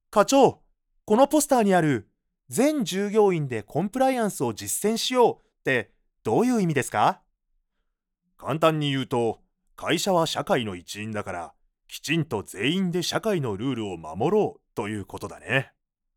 I can adapt my voice to your needs, from a calm tone to a bouncy, energetic voice.
Can speak Kansai dialect.
– Voice Actor –
Conversation between subordinates and supervisors